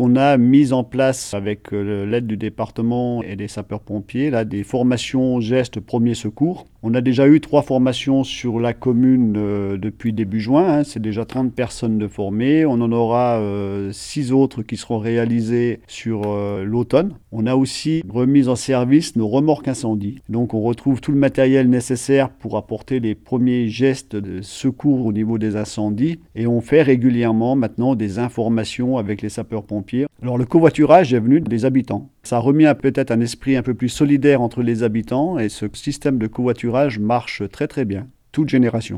En attendant la fin du chantier, pour aider les habitants et leur apporter un maximum d’outils sécurisants, plusieurs dispositifs ont été mis en place comme l’explique Alain Roux, le maire de Nancy-sur-Cluses.